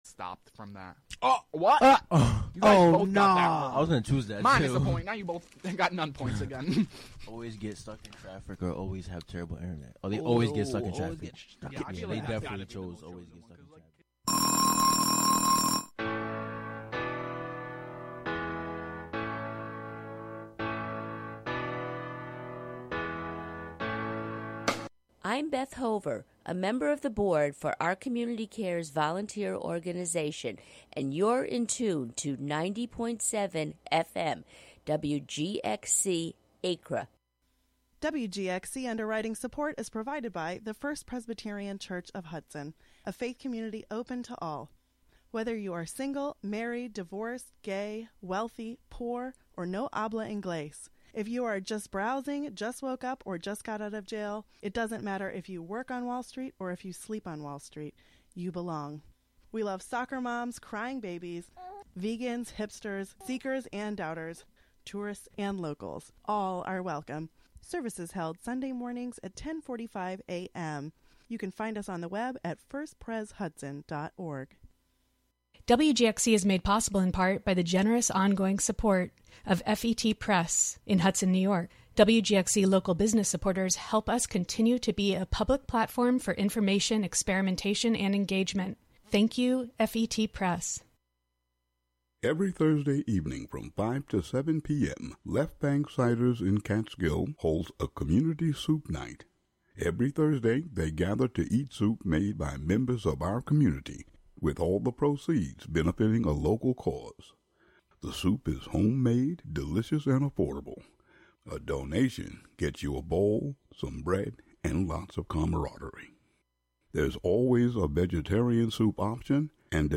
This music mix show